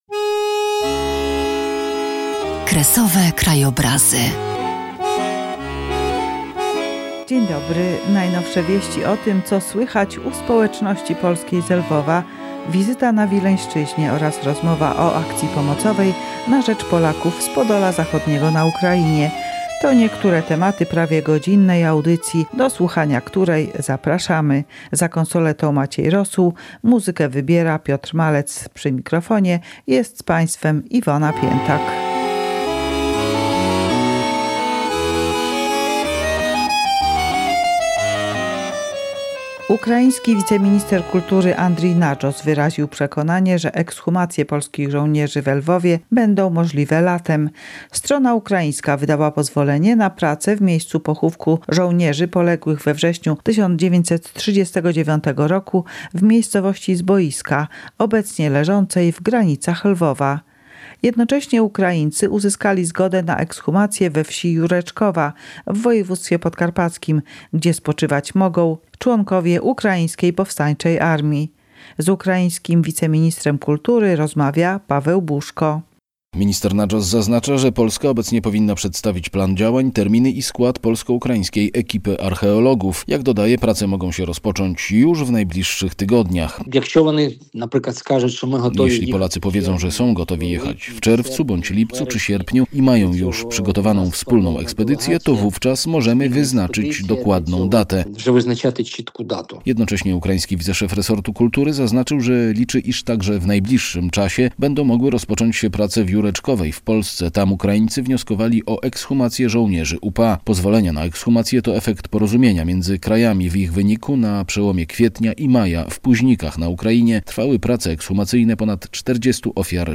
W programie znalazł się także magazyn Radia Lwów, przygotowany przez polską redakcję we Lwowie, który jak zawsze przybliżył codzienność i aktywność kulturalną naszych rodaków na dawnych Kresach.